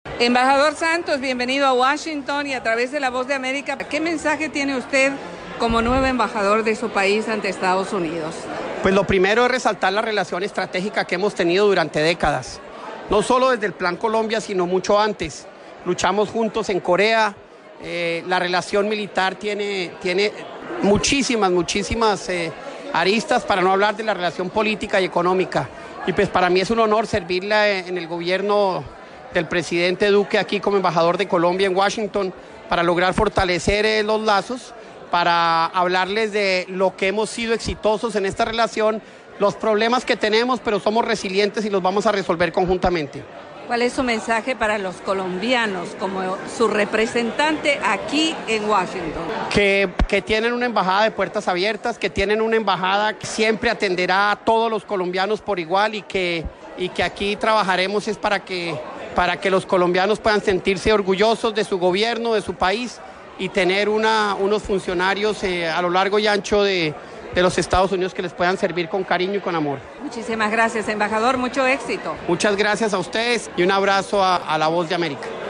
El nuevo embajador de Colombia en EE.UU., Francisco Santos, habla con la VOA